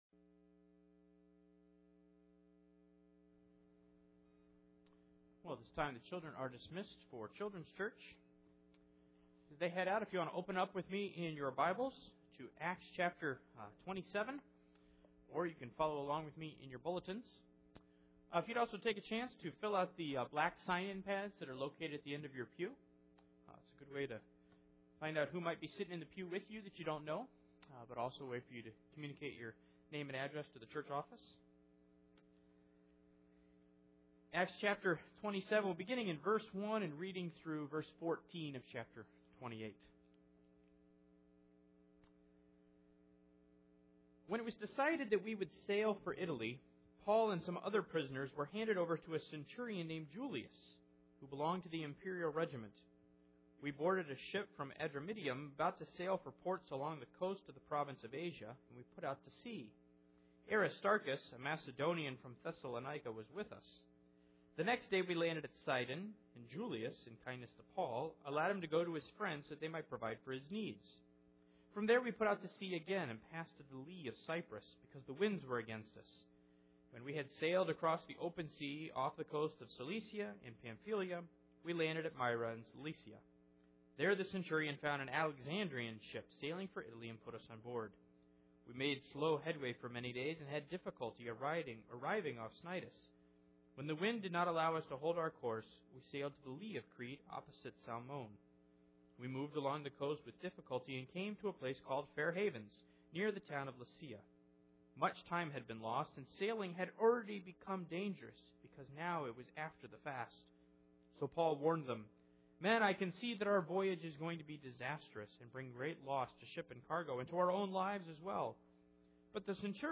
Acts 27:1-28:14 Service Type: Sunday Morning Paul finds himself on a boat in a northeaster destined for a shipwreck.